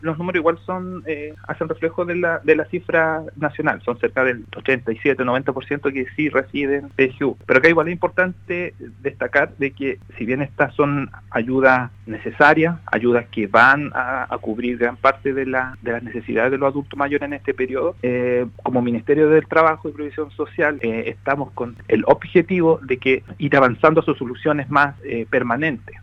En conversación con el programa “Primera Hora” de Radio Sago, el seremi del Trabajo y Previsión Social de Los Lagos, Ricardo Ebner, indicó que desde este mes se comenzó a entregar el bono invierno 2024, el cual se depositará automáticamente en las cuentas de los pensionados, que cumplan con los requisitos legales en todo el país.